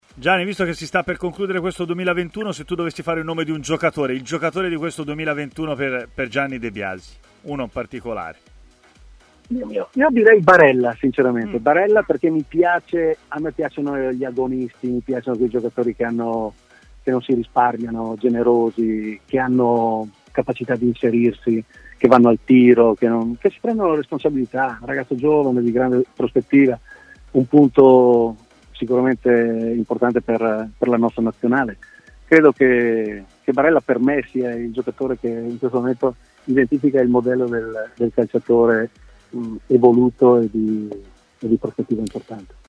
L'allenatore Gianni De Biasi, ct dell'Azerbaigian, ha così parlato a Stadio Aperto, trasmissione di TMW Radio: "Più di una squadra mi ha sorpreso.